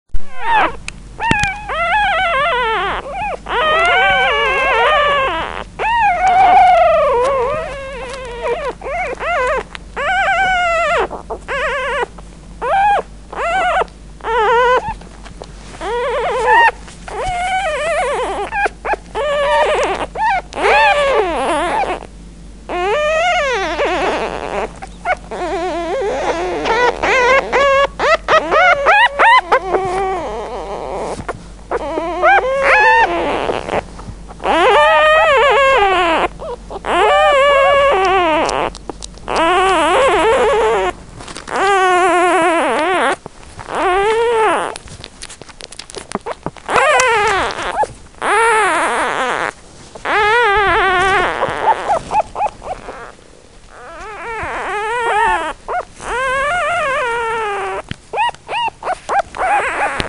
Oggi non riesco ad aggiungere altro; ma darò voce ai quattro fratellini e vi invito ad ascoltare quello che vogliono dirci, prima, durante e dopo il pasto (con il biberon).
2-Mugulio durante il pasto